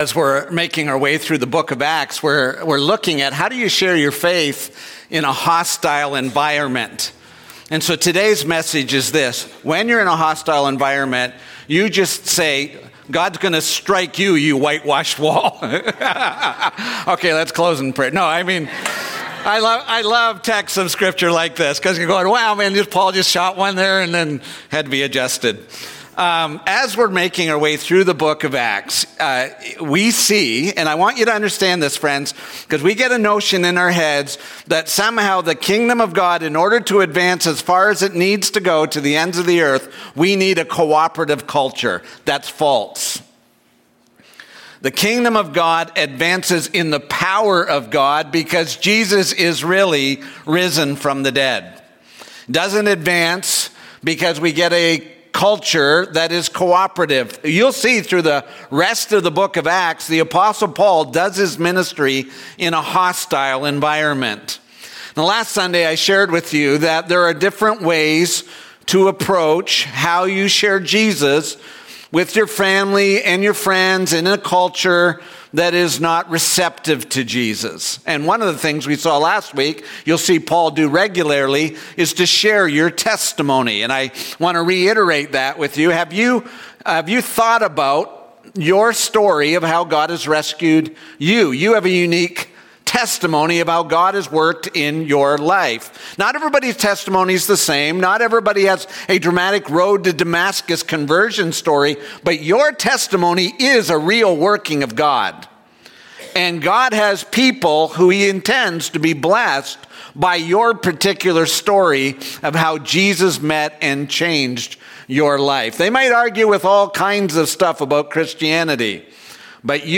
Sermons | Waterbrooke Christian Church